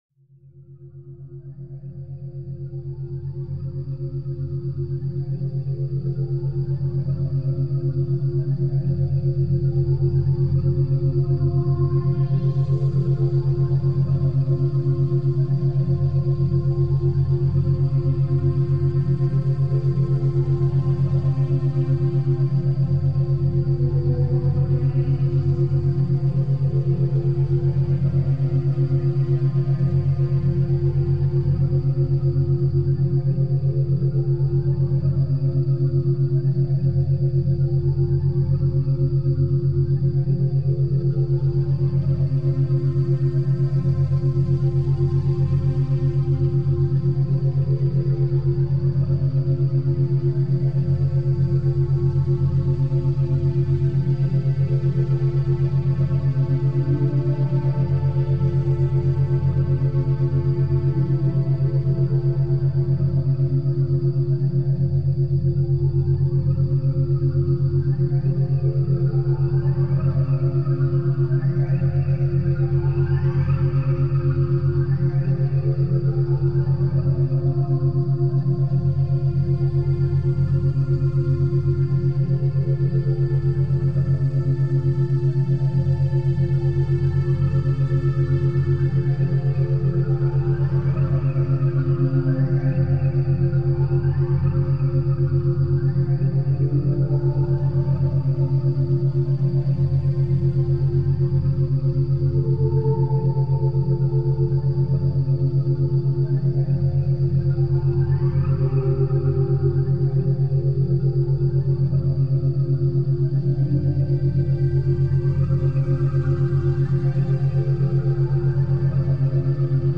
Pomodoro 3h : Pluie Relaxante